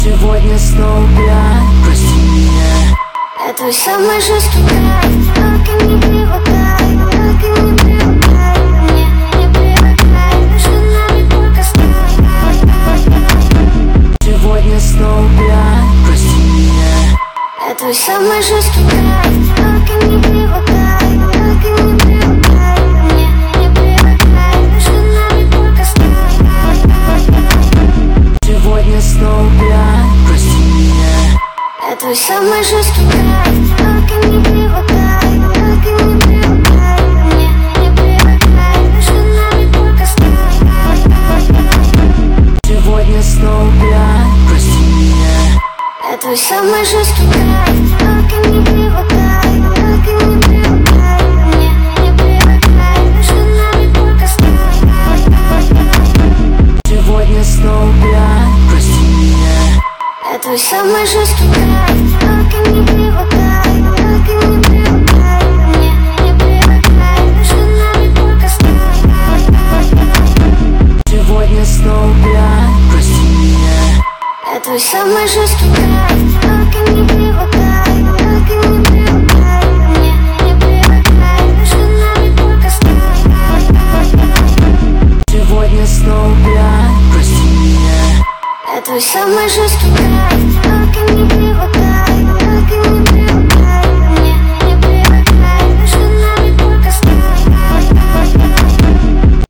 Новинки русской музыки
brazilian phonk